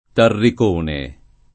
Tarricone [ tarrik 1 ne ]